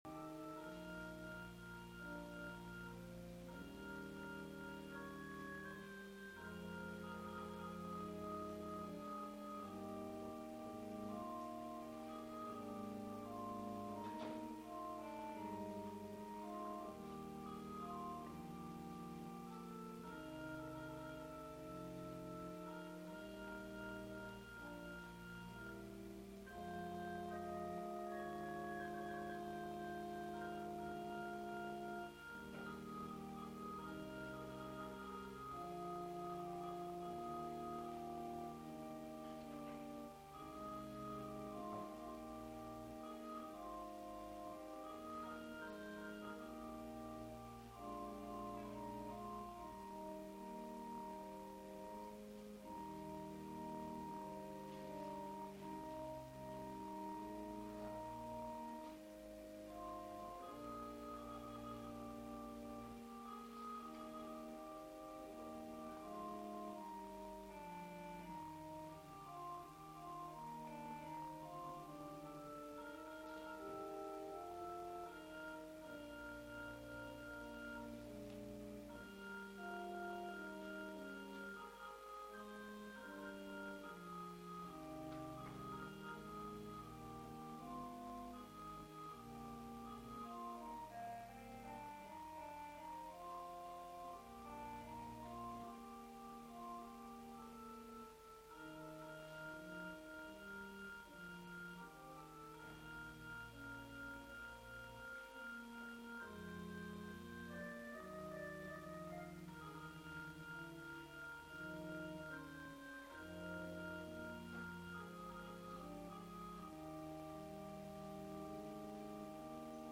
主 日 礼 拝 2020年3月15日 午前10:30
＜音声のみ＞礼拝全体の録音になっています。